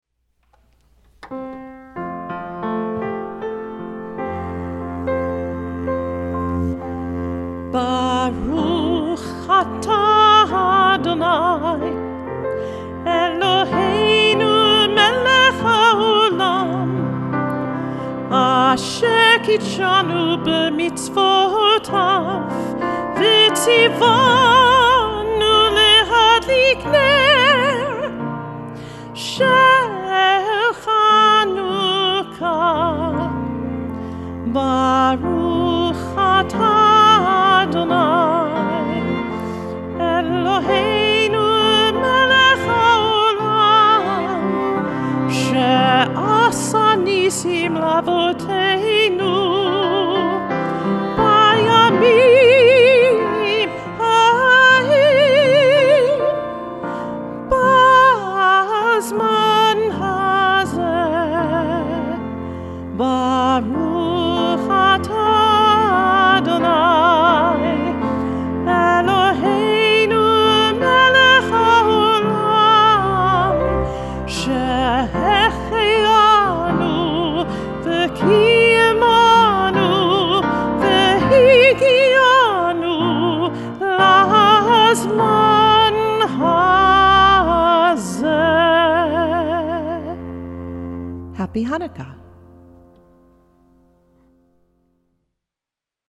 Chanukah Songs and Blessing
Chanukah-Candle-Blessing.mp3